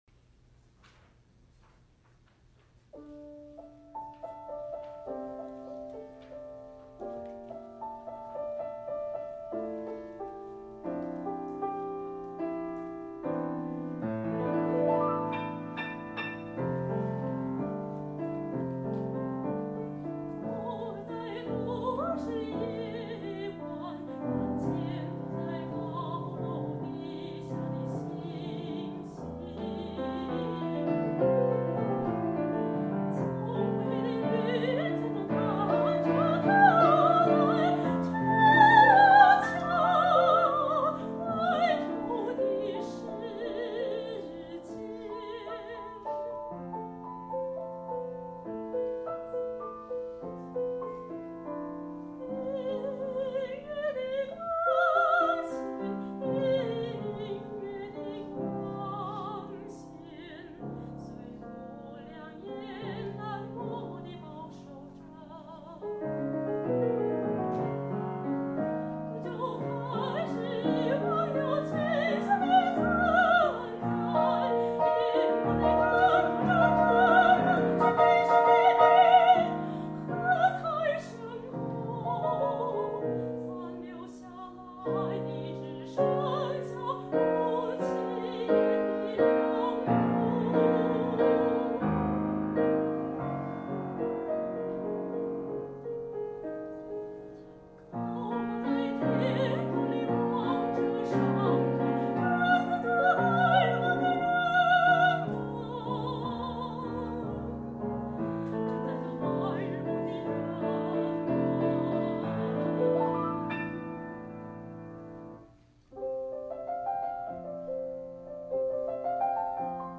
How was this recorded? Bach Recital Hall, Taipei, Taiwan This is the world premiere recording.